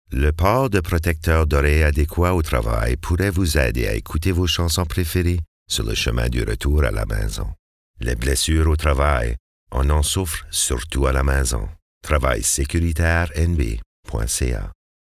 Annonces à la radio